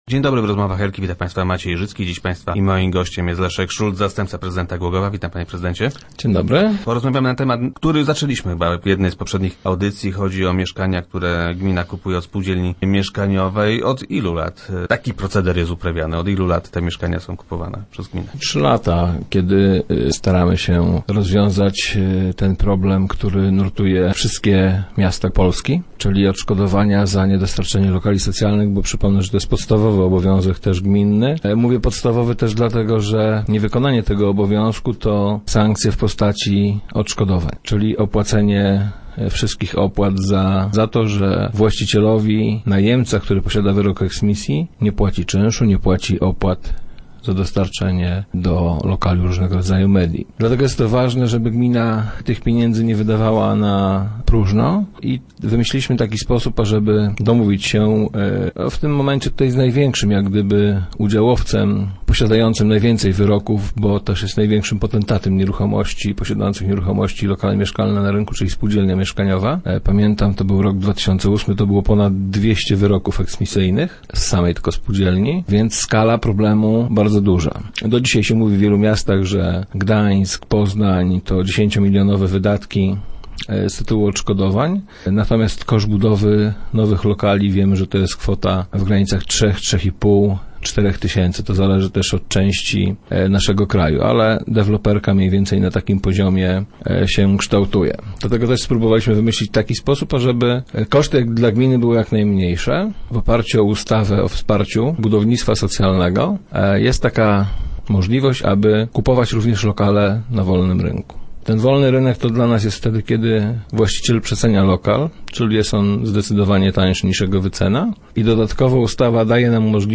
Start arrow Rozmowy Elki arrow Szulc: Ten system się sprawdza
Otrzymaliśmy właśnie potwierdzenie otrzymania takiej dopłaty z Banku Gospodarstwa Krajowego za lokale kupione w ubiegłym roku - mówił na radiowej antenie wiceprezydent Szulc.